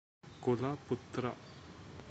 Kn-ಕುಲ_ಪುತ್ರ.ogg.mp3